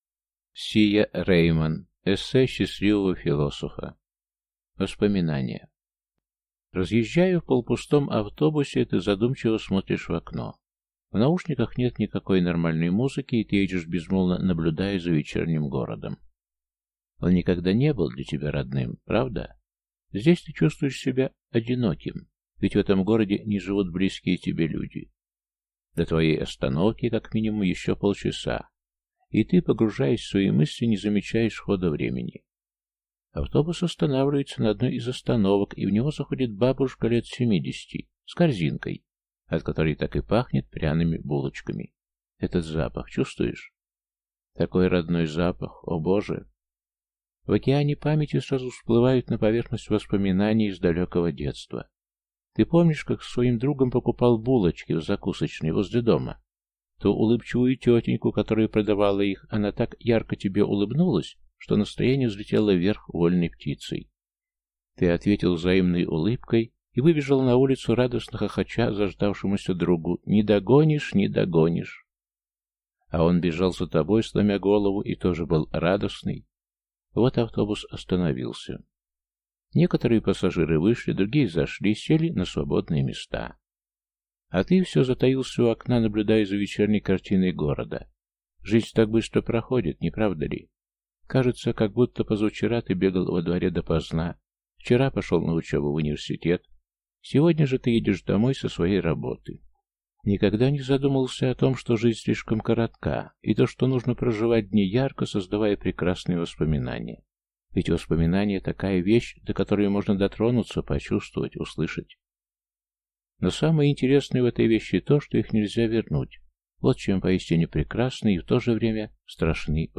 Аудиокнига Эссе счастливого философа | Библиотека аудиокниг